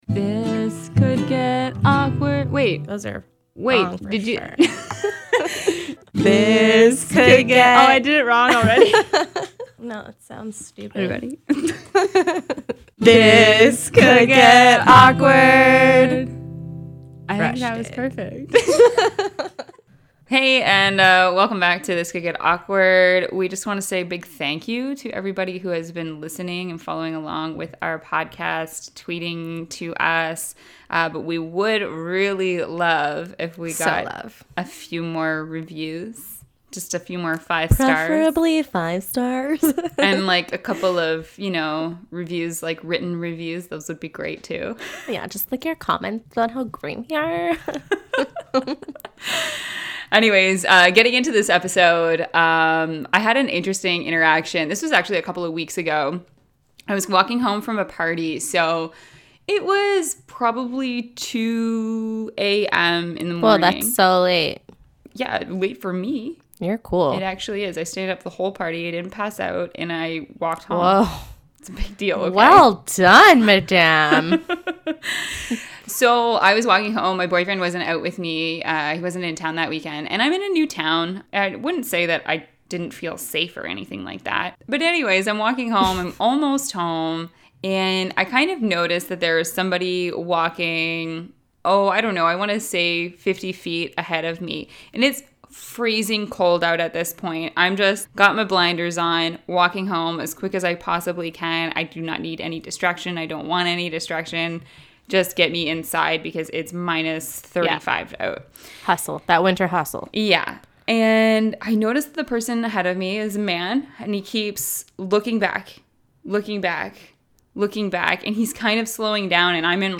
This Could Get Awkward » Page 20 of 65 » A podcast by two awesome women.